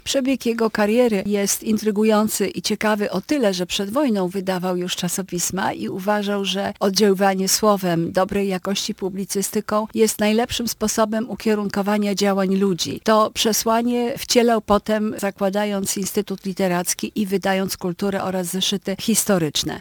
[Poranna Rozmowa] Lublin upamiętnia Jerzego Giedroycia – przed nami wystawy i sesje naukowe.